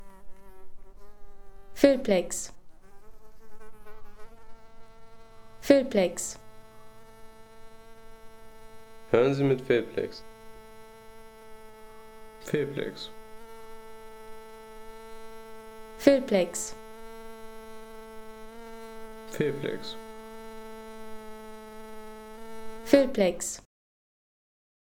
Wespenschwebfliege